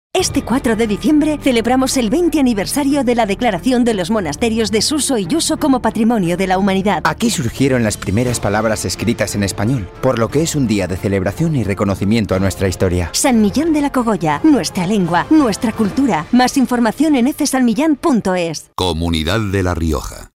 XX Aniversario de San Millán - Publicidad institucional - Portal del Gobierno de La Rioja